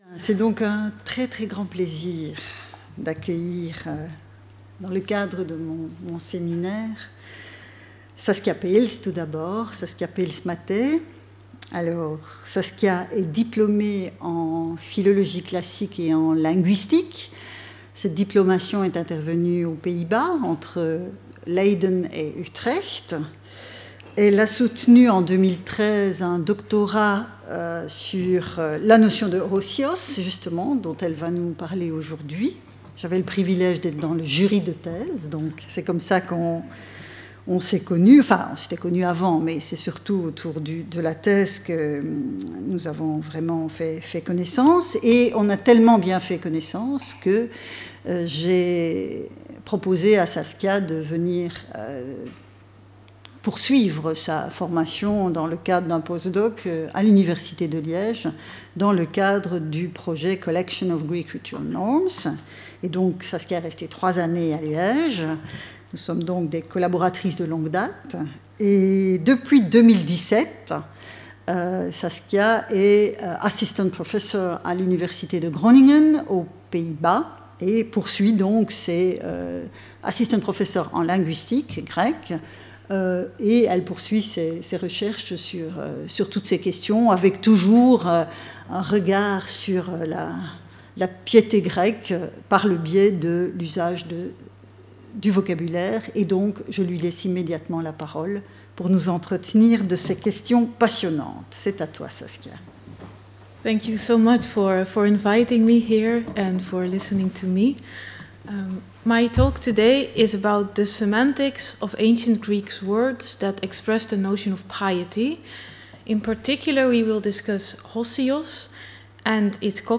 Skip youtube video player Listen to audio Download audio Audio recording Abstract In this lecture we will address hosiotēs , one of the ancient Greek words that refers to "piety". Hosiotēs was a key notion in classical Greek religion, reflecting a core value in Athenian democracy. The term refers to what humans should do to please the gods and to obey (unwritten) religious norms.